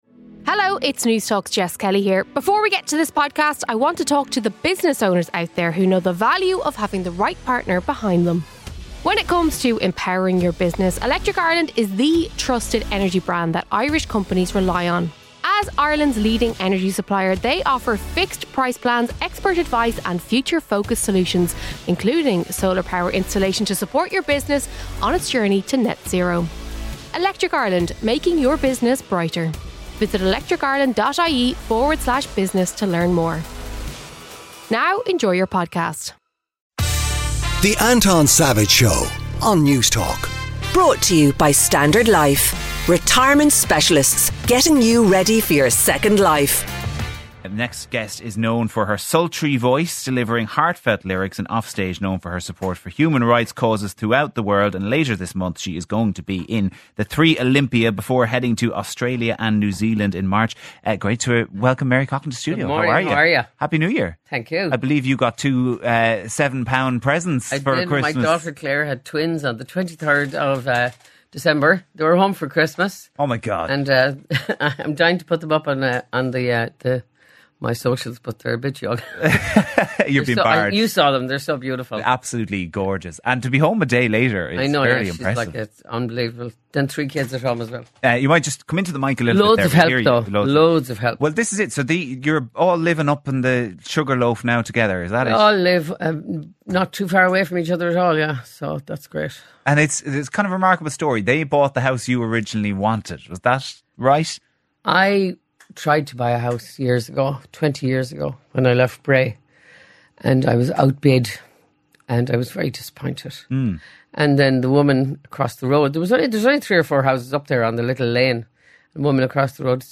Interview: Mary Coughlan.